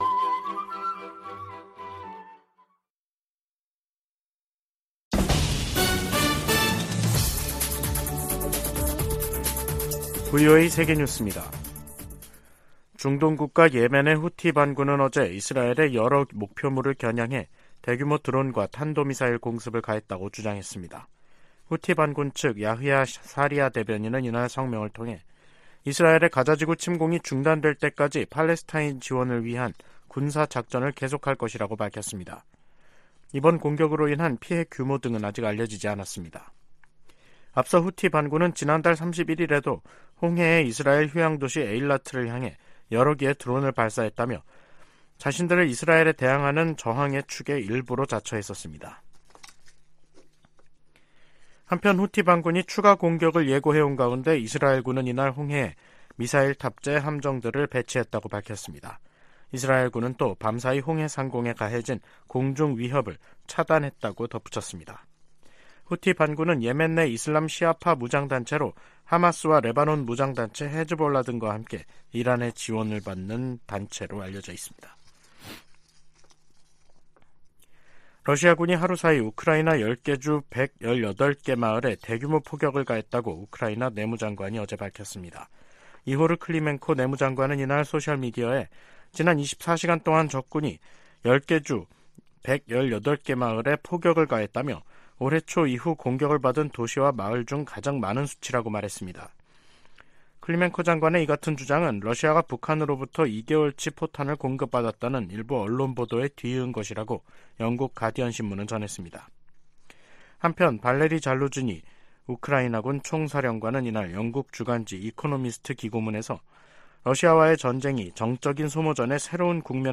VOA 한국어 간판 뉴스 프로그램 '뉴스 투데이', 2023년 11월 2일 2부 방송입니다. 미 국방정보국장이 북한-이란-러시아 연계를 정보 당국의 주시 대상으로 지목했습니다. 조 바이든 미국 대통령이 공석이던 국무부 부장관에 커트 캠벨 백악관 국가안보회의 인도태평양 조정관을 공식 지명했습니다. 북한의 최근 잇따른 대사관 폐쇄는 국제사회의 제재가 작동하고 있다는 증거라고 미국 전직 외교관들이 분석했습니다.